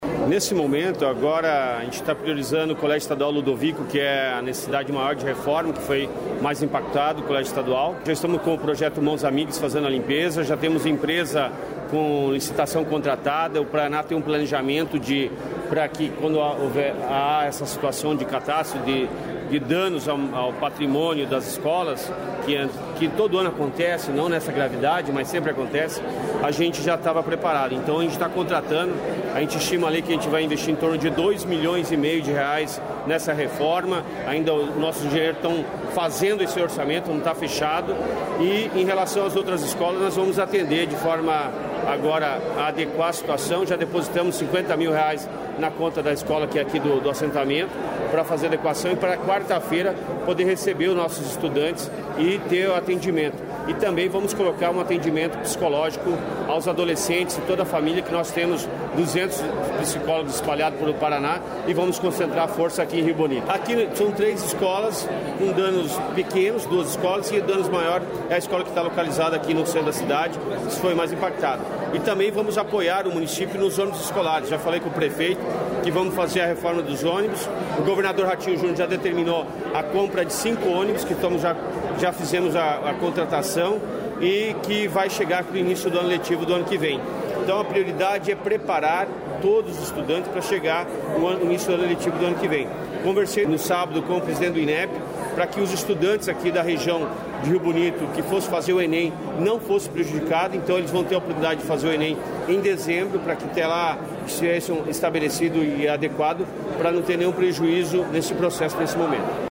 Sonora do secretário estadual da Educação, Roni Miranda, sobre a reconstrução das escolas em Rio Bonito do Iguaçu